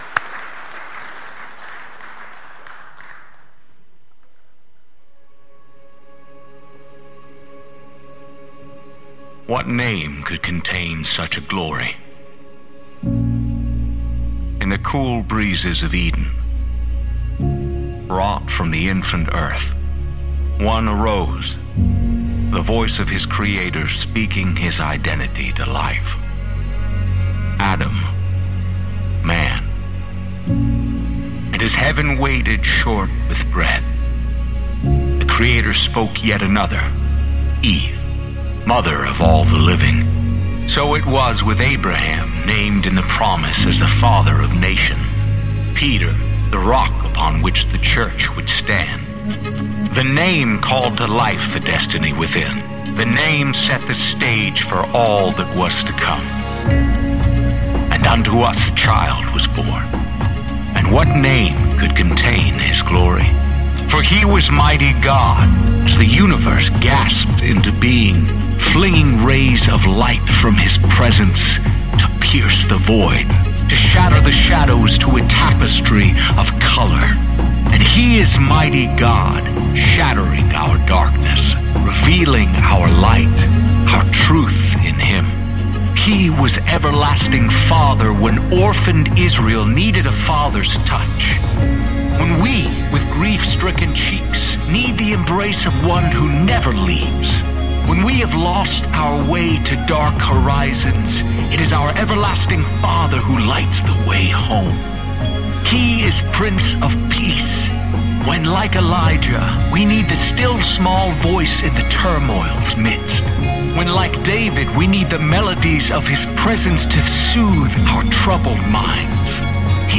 Sermons | Enterprise Christian Church